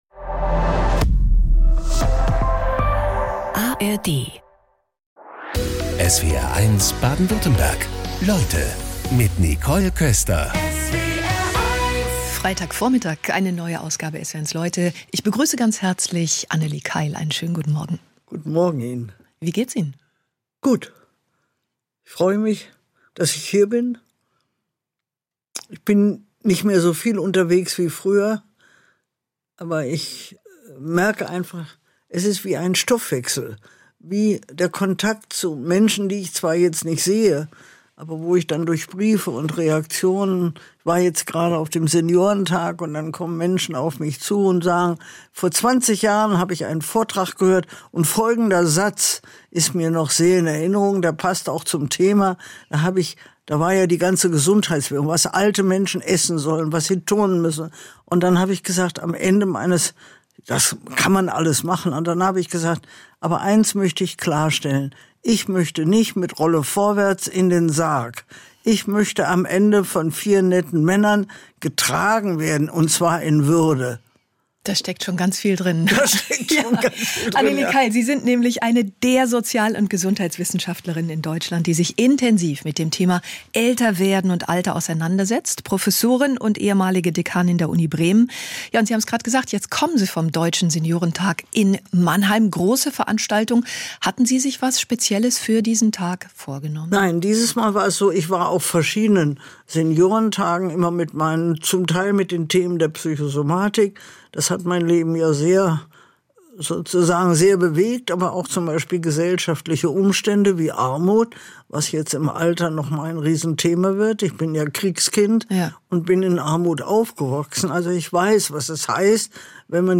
Talks mit besonderen Menschen und ihren fesselnden Lebensgeschichten aus Politik, Sport, Wirtschaft oder Wissenschaft.